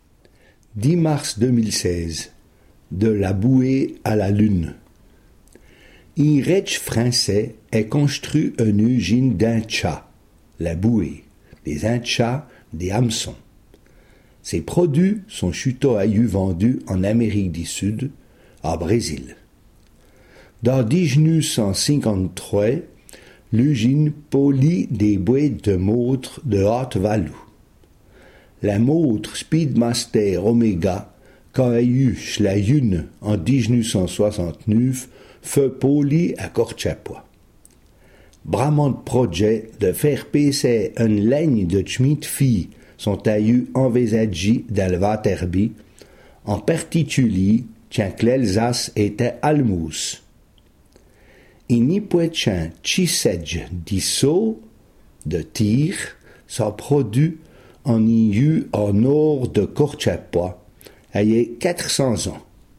Sommaire Ecouter le r�sum� en patois Panneau complet, consulter ou Situation ; coordonn�es (...)